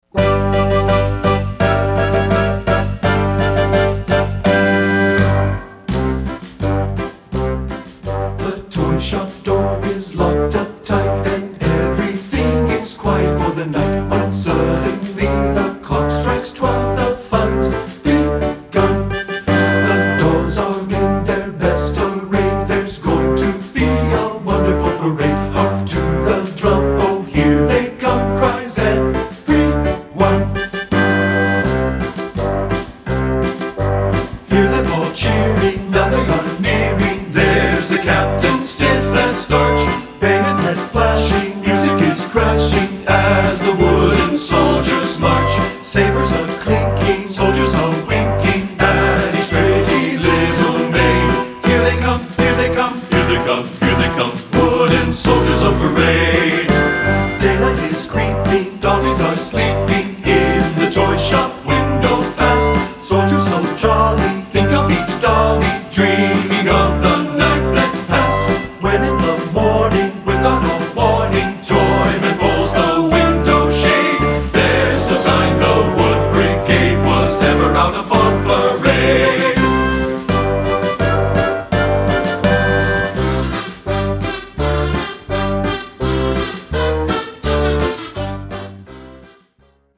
Choral Christmas/Hanukkah Light Concert/Novelty